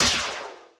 BLLTRico_Metallic_11.wav